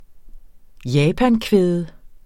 Udtale [ ˈjaːpan- ]